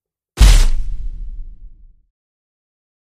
Вы можете слушать и скачивать мощные удары по стенам, грохот бронзового наконечника и другие эффекты, воссоздающие атмосферу средневековых битв.
Звук выбивания двери тараном